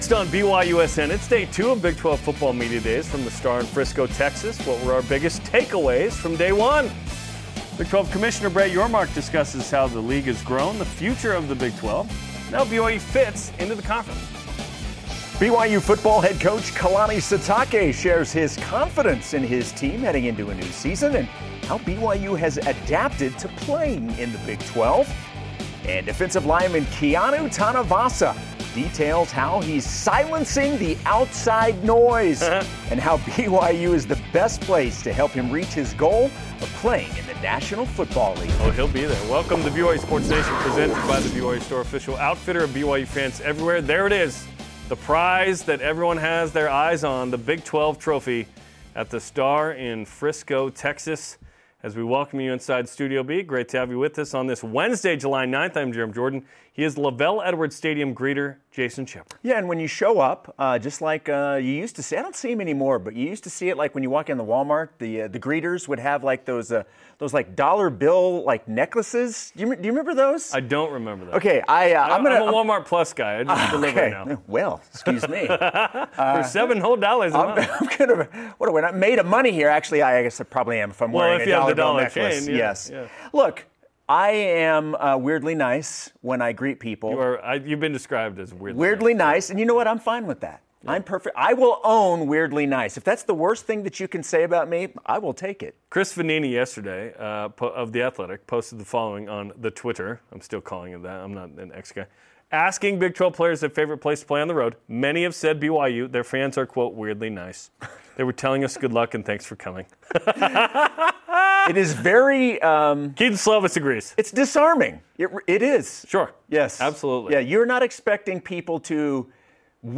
You’ll get in-depth play analysis, athlete interviews, and gripping commentary on all things BYU Athletics. Previous BYU Football Media Day 1 Next Egor's NBA Summer League Debut ← See all 3368 episodes of BYU Sports Nation